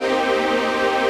CHRDPAD099-LR.wav